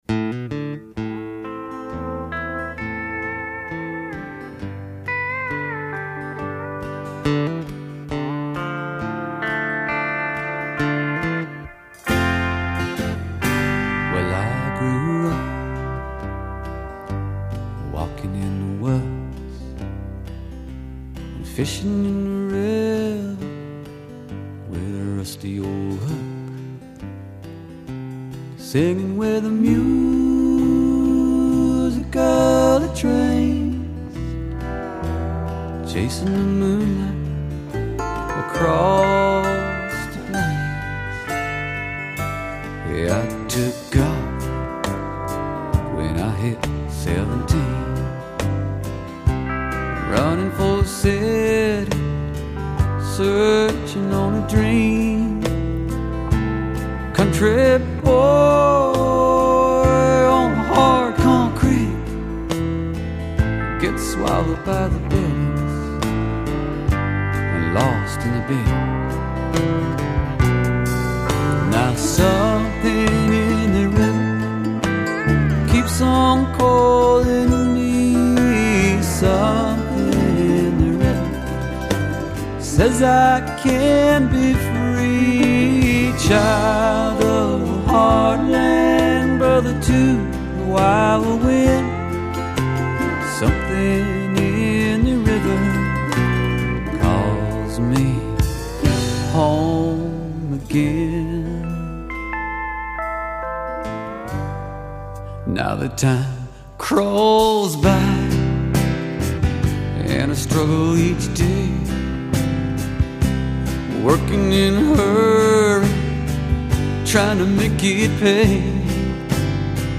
vocals, guitar
Bass
Drums
Pedal steel, mandolin